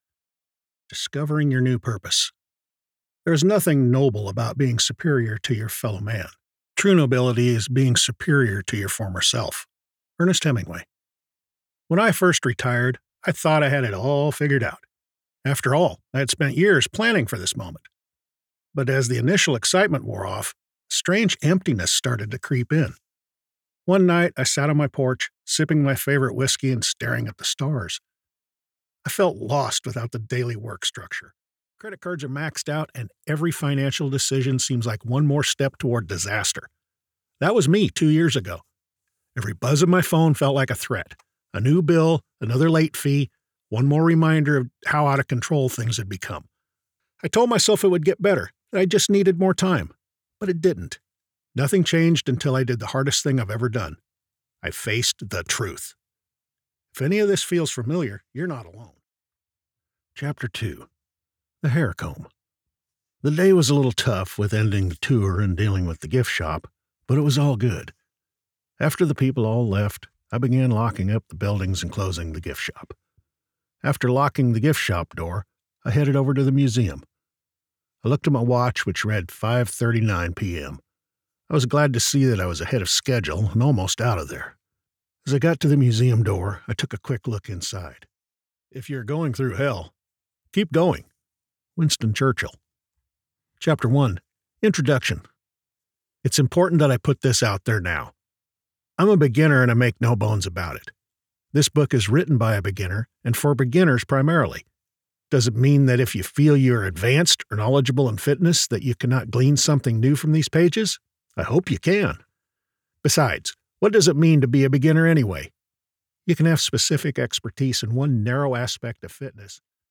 Male
Adult (30-50), Older Sound (50+)
Words that describe my voice are Storyteller, Conversational, Relatable.
0923Audiobook_demo.mp3